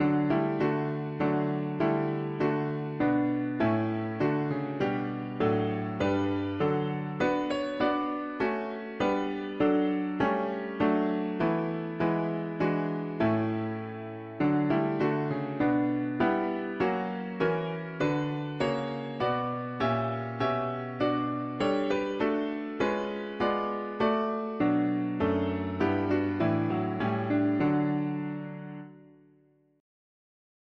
Lyrics: In our day of thanksgiving one psalm let us offer for the saints who before us have found the reward; when the shadow of death fell upon them, we sorr… english theist 4part death chords
Key: D major